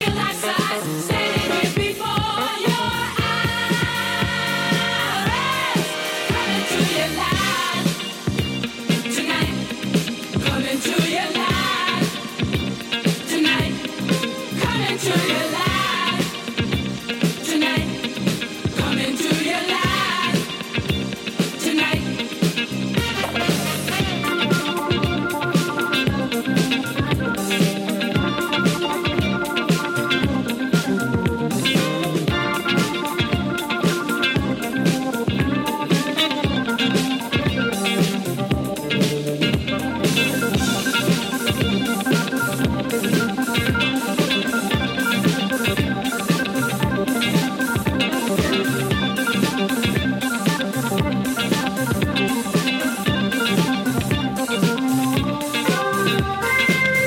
jazz funk and soul
organ